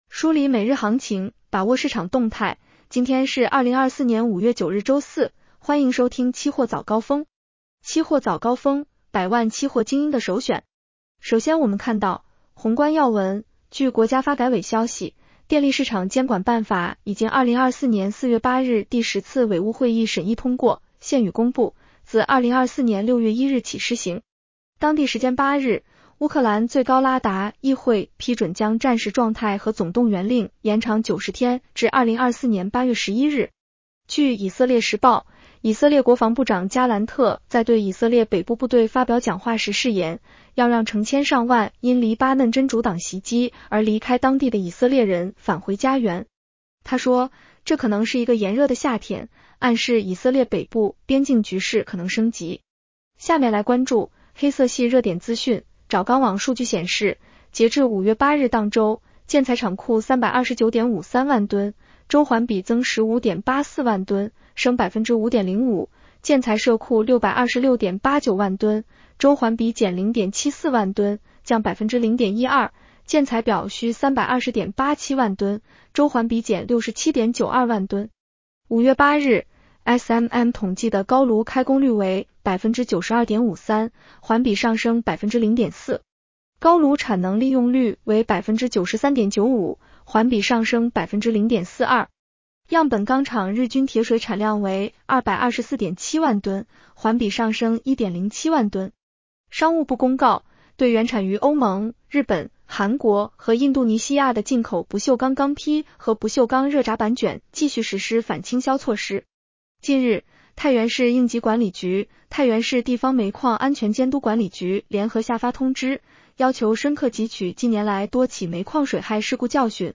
梳理每日行情，把握市场动态，各位听众朋友早上好，今天是2024年5月9日星期四。欢迎收听《期货早高峰》。期货早高峰，百万期货精英的首选！